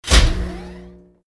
LB_laser_beam_on_2.ogg